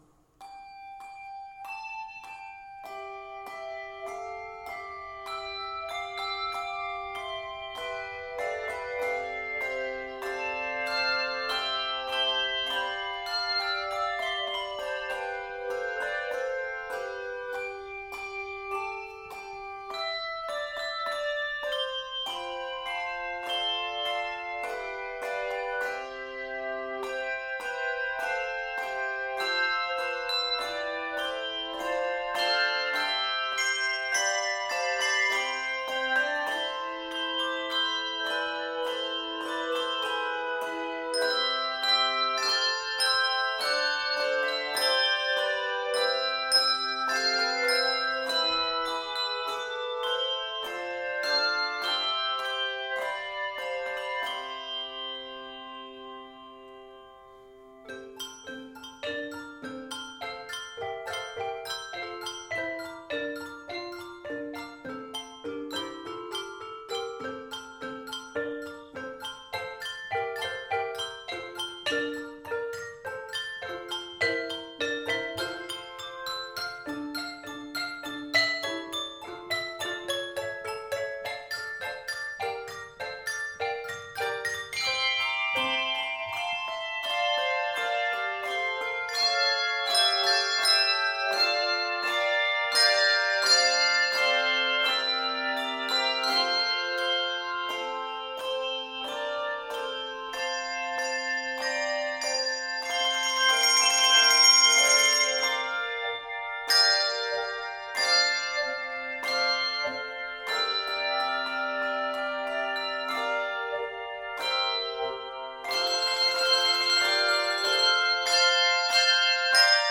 Keys of C Major and F Major.
Octaves: 2-3